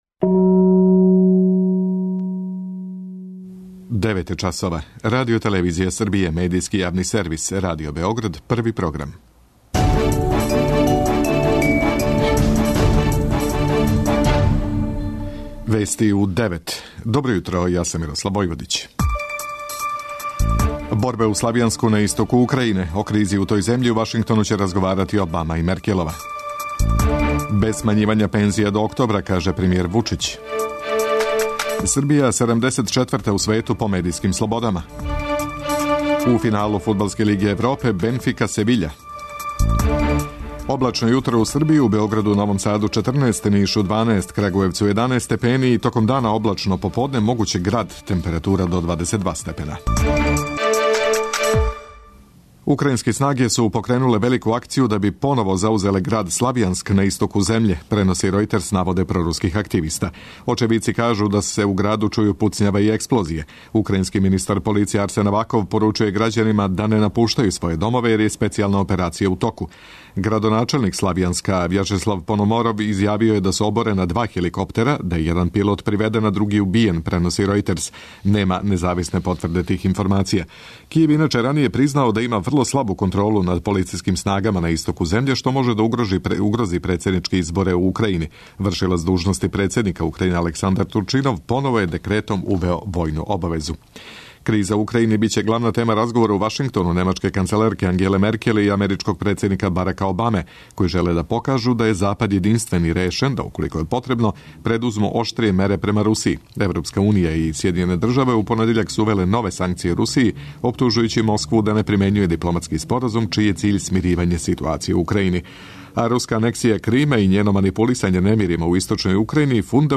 преузми : 9.16 MB Вести у 9 Autor: разни аутори Преглед најважнијиx информација из земље из света.